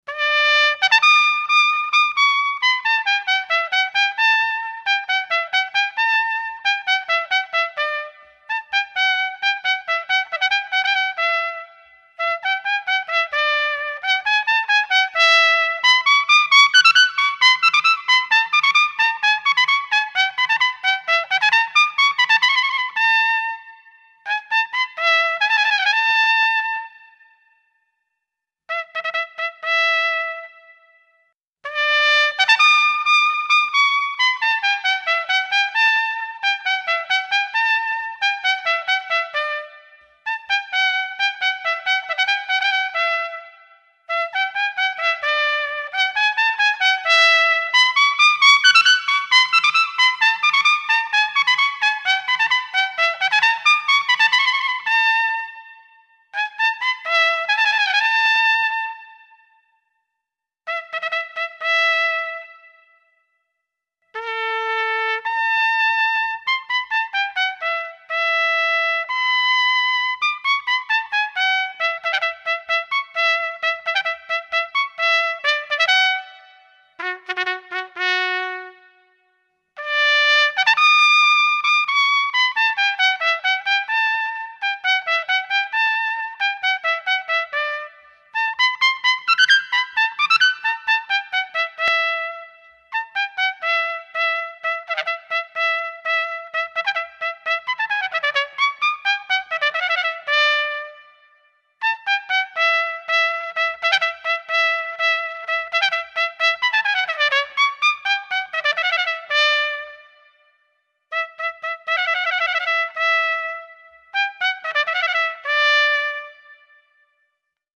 Tout sur la trompette
Concerto de Franz Xaver Richter (70 ko) : un autre défi pour les spécialiste de l'extrême aigu.